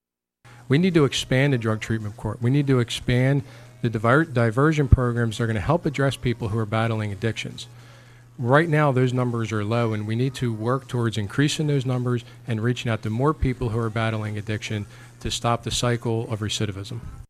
This morning on WCCS AM 1160 and 101.1 FM, two debates were held concerning two positions of county government that are on the ballot for next week’s election.